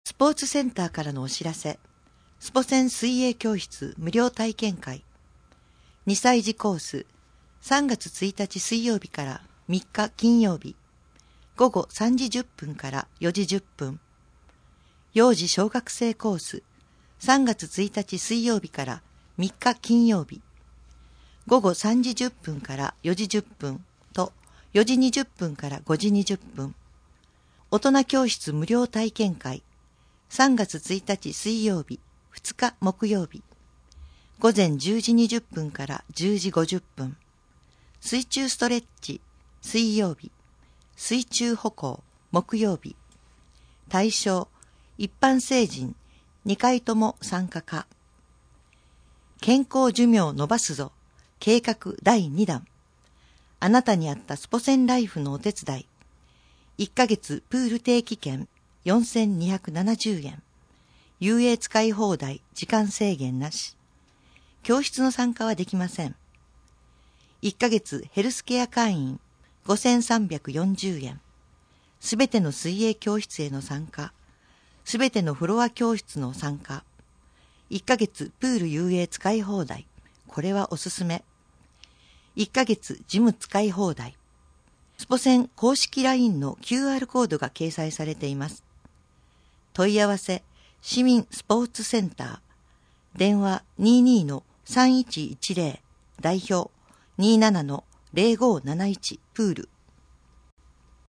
なお「声の広報」は、朗読ボランティアどんぐりの協力によって作成しています。